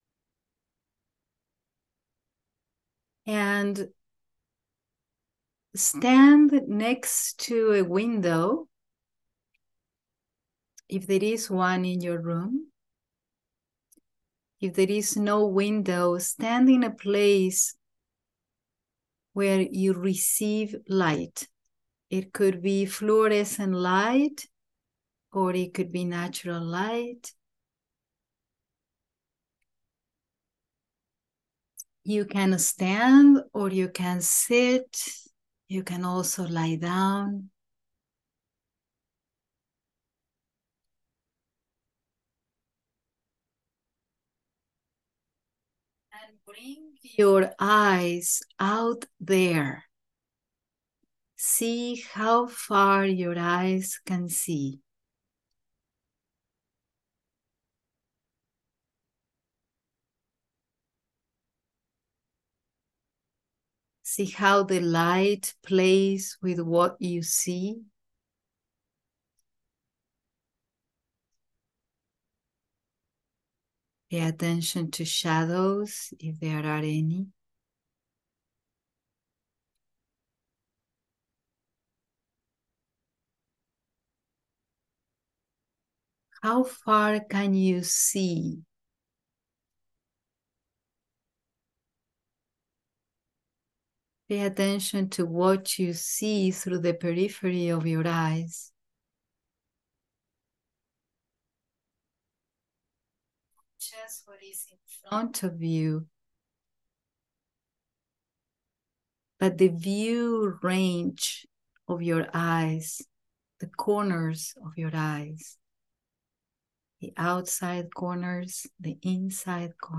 9edfa-animal-body-meditation.m4a